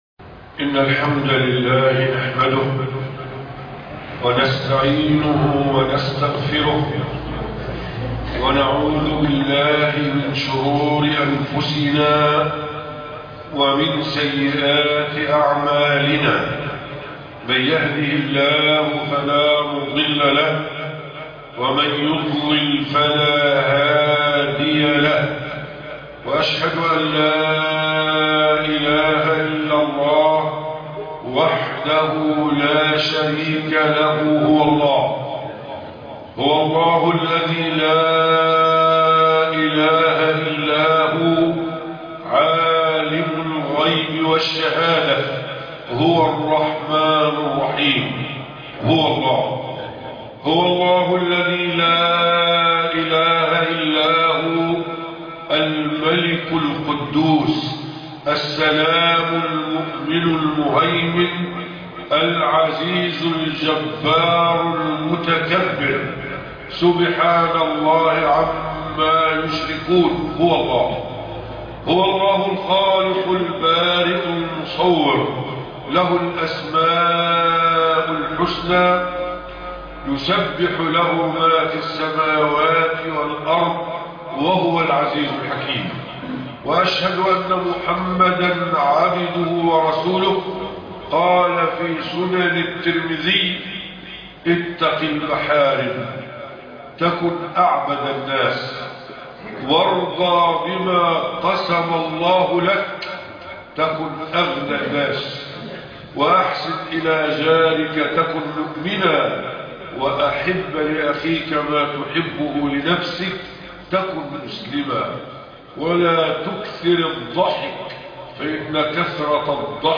خطبة بعنوان (أمرني رسول الله)